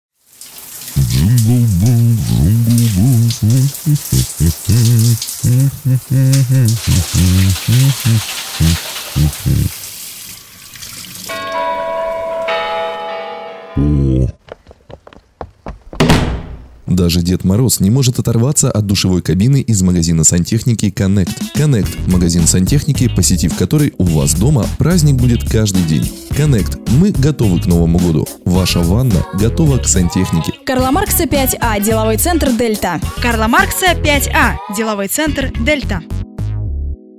Радиоролик магазина сантехники (сценарий) Категория: Копирайтинг